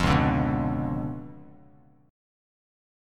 C#7b9 chord